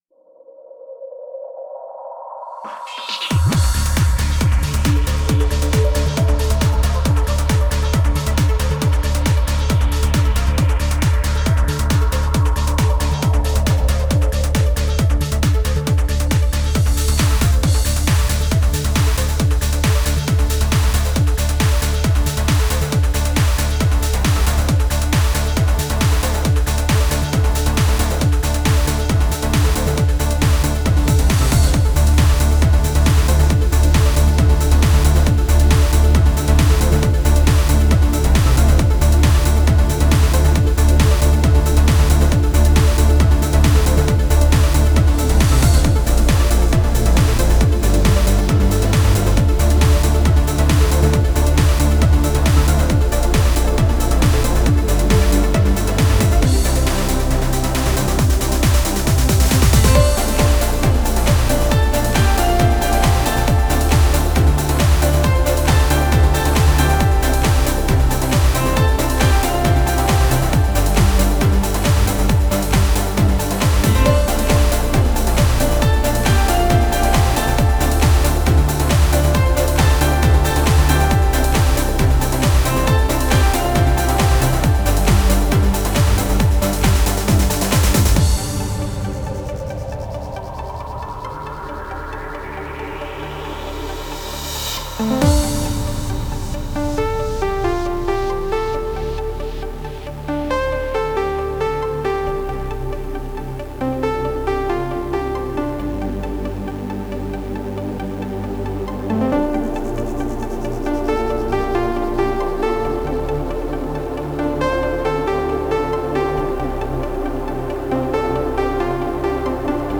like super heavy mud xD
Song Platform: Utility » PC Trackers (S3M / XM / IT / ...)